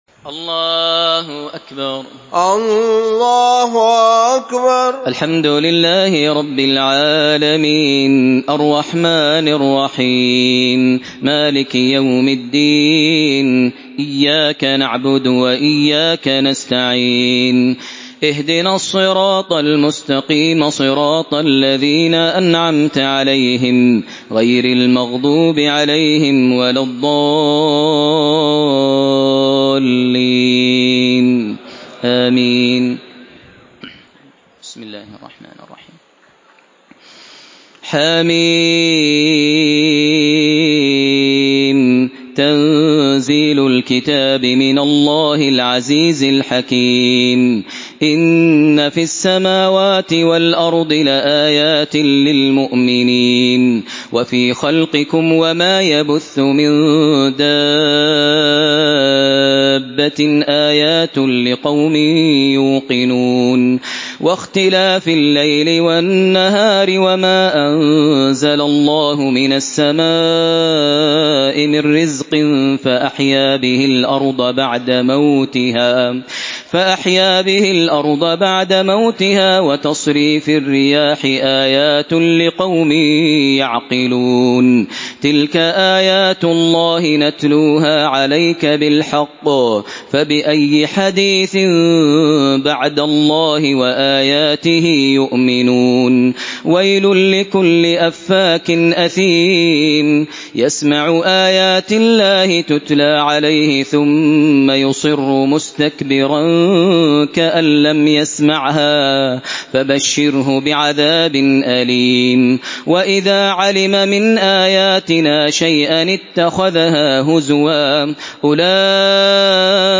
Surah Casiye MP3 by Makkah Taraweeh 1432 in Hafs An Asim narration.
Murattal